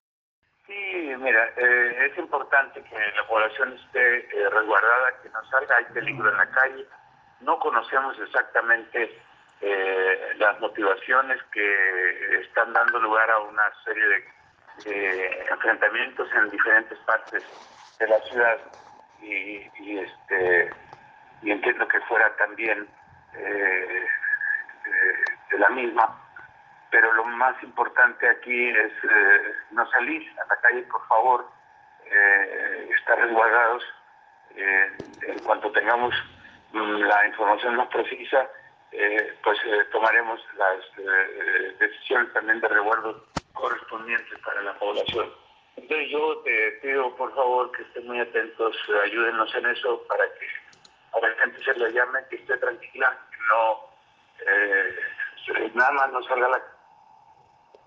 El mandatario estuvo en entrevista en la primera emisión de Los Noticieristas y señaló que de momento no tiene información precisa de lo que está ocurriendo salvo que hay enfrentamientos, bloqueos y un operativo muy fuerte por parte de fuerzas federales.
HABLA EL GOBERNADOR DEL ESTADO RUBEN ROCHA MOYA: